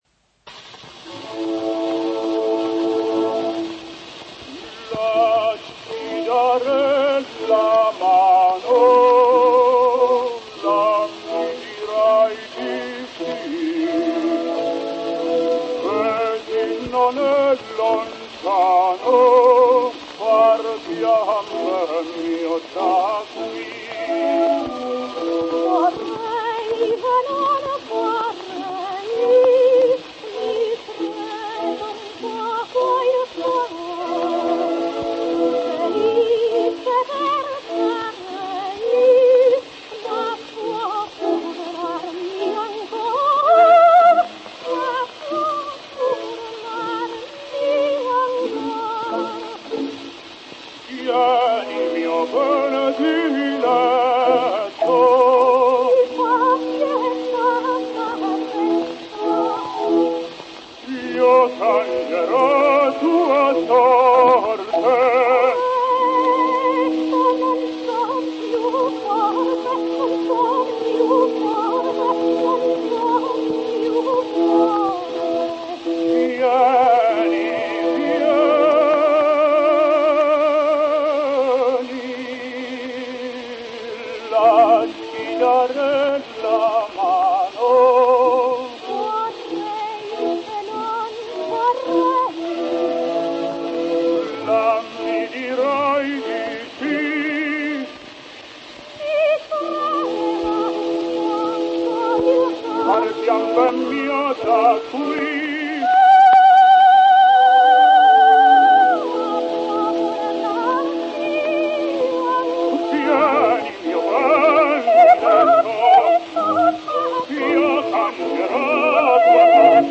Титта Руффо,Грациелла Парето-дуэт Дона Жуана и Церлины.mp3